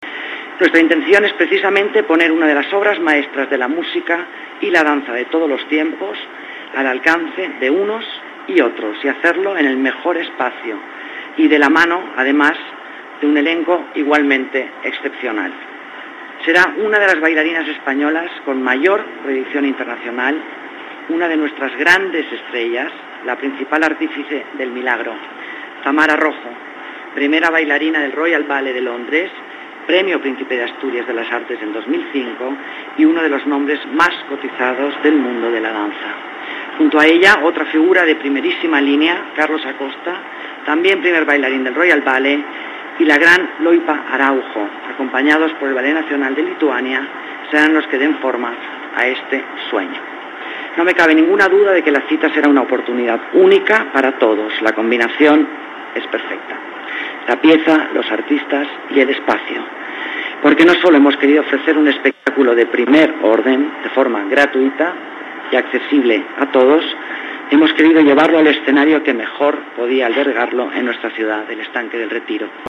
Nueva ventana:La concejala del Área de Las Artes, Alicia Moreno, presenta el espectáculo Una noche en el lago de los cisnes